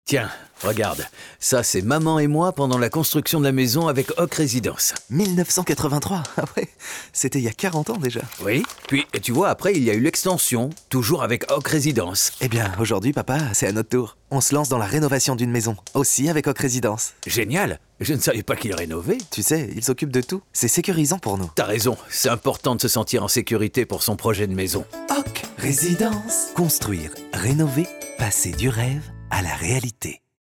Natural, Versatile, Friendly